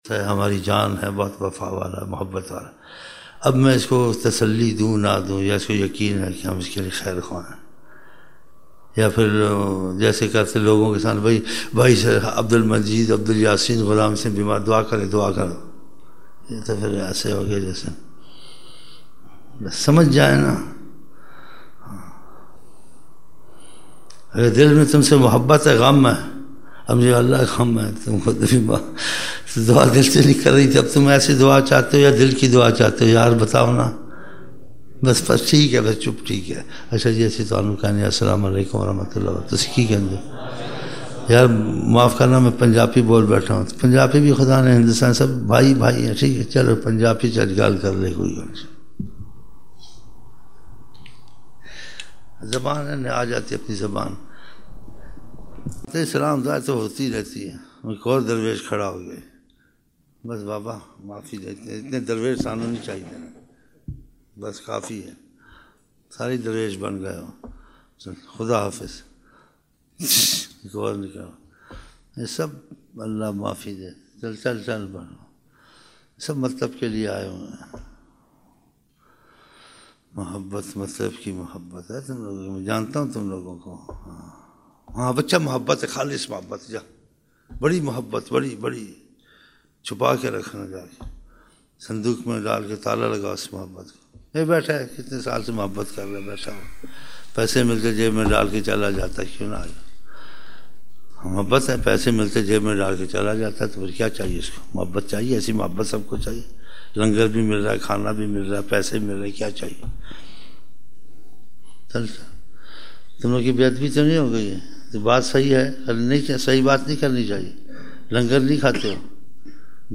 15 November 1999 - Isha mehfil (7 Shaban 1420)